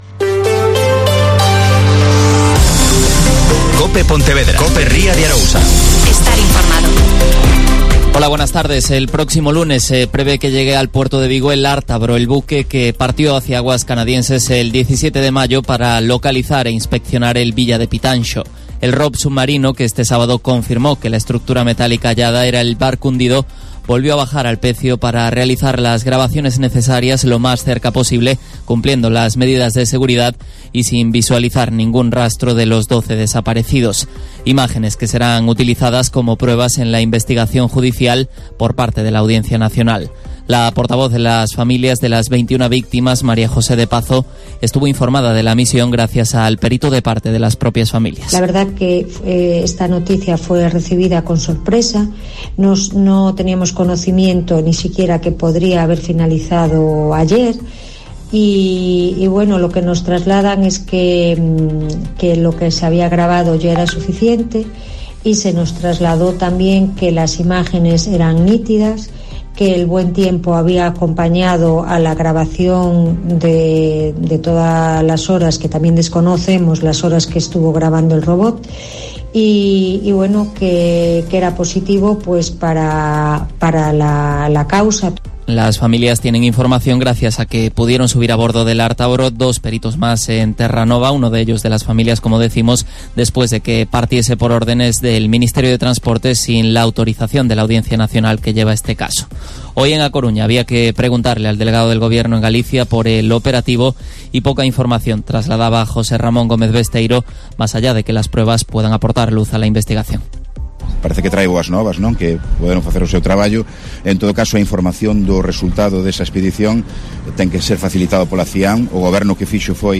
Mediodía COPE Ría de Arosa (Informativo 14:20h)